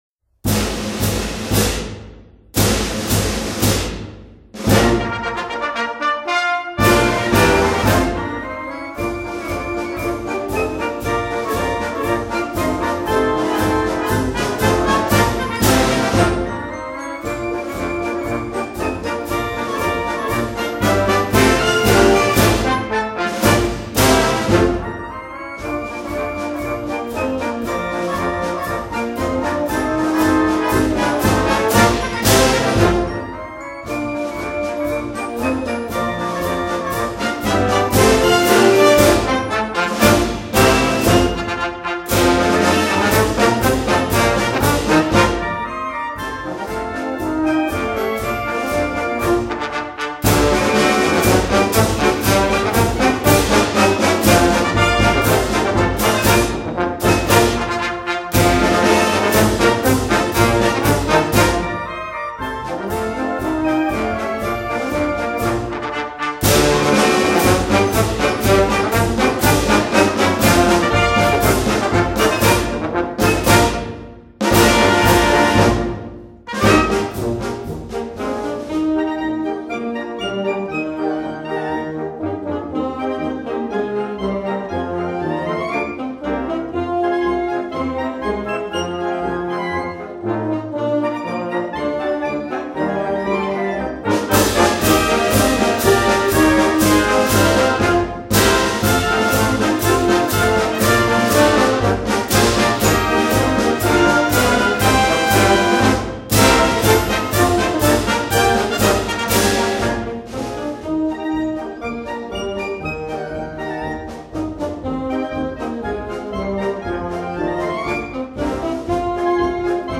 quick march